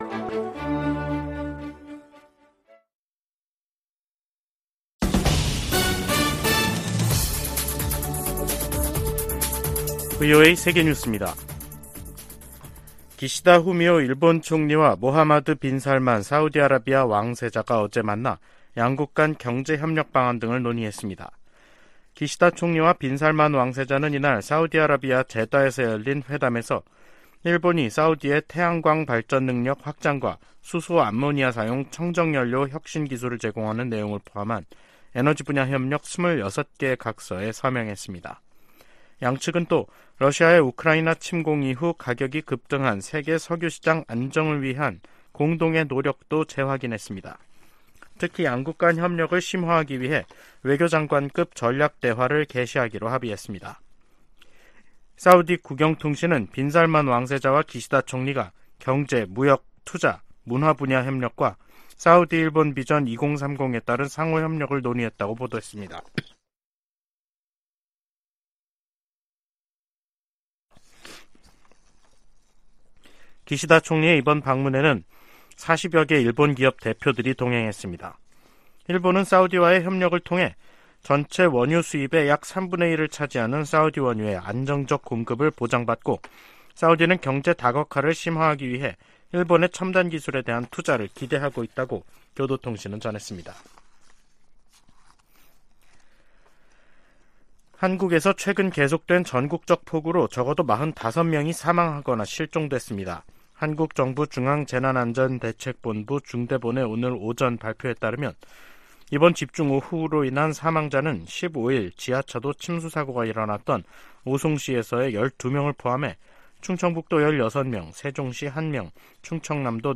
VOA 한국어 간판 뉴스 프로그램 '뉴스 투데이', 2023년 7월 17일 2부 방송입니다. 김여정 북한 노동당 부부장이 담화를 내고 대륙간탄도미사일 '화성-18형' 발사의 정당성을 주장하면서 미국을 위협했습니다. 인도네시아 자카르타에서 열린 제30차 아세안지역안보포럼(ARF) 외교장관회의에서 미한일 등 여러 나라가 북한의 탄도미사일 발사를 규탄했습니다. 미 상원에서 한국 등 동맹국의 방위비 분담 내역 의회 보고 의무화 방안이 추진되고 있습니다.